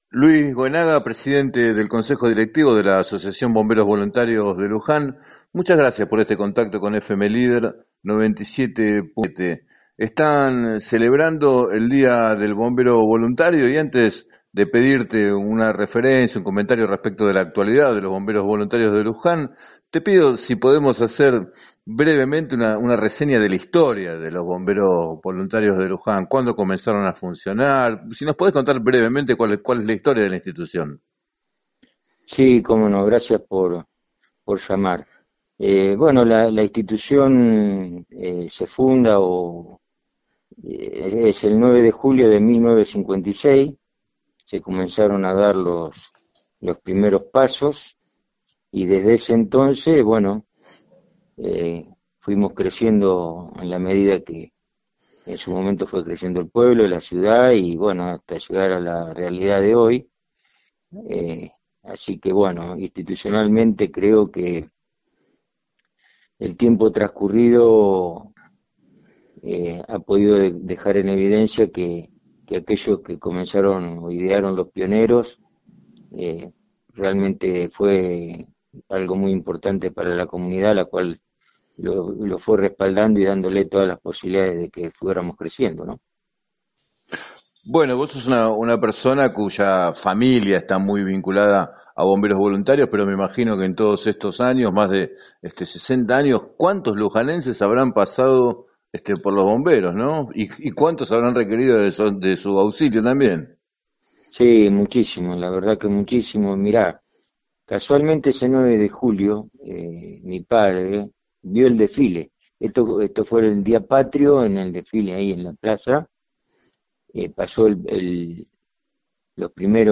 en declaraciones al programa “7 a 9 Música y Noticias” de FM Líder 97.7